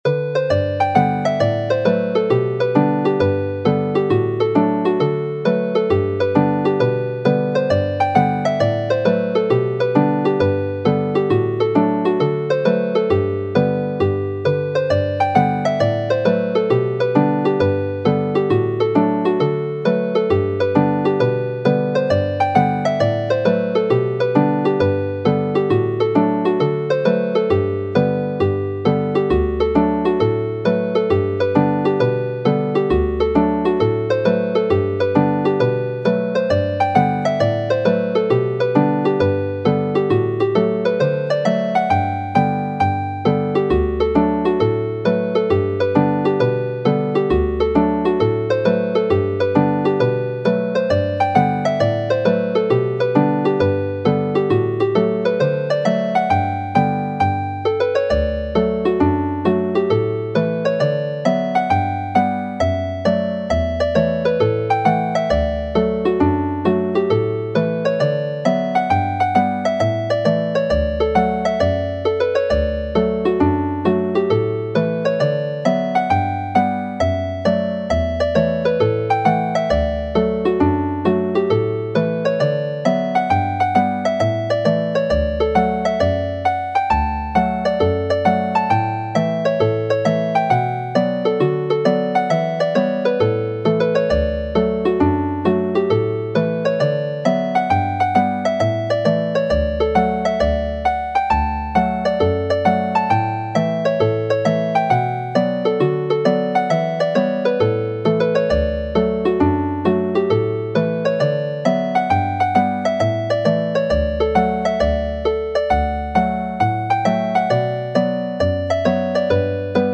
Pibddawnsiau Cymreig - Set Ionawr 2018 - Welsh hornpipes to play
Hornpipes run with a skip which is more accurately represented by writing them in 12/8 time: four groups of three beats.
Pibddawns Heol y Felin (Mill Street) and Pibddawns Dowlais appear in this form in earlier sets in this sreries and play as reels but in this set they are played with the hornpipe skip.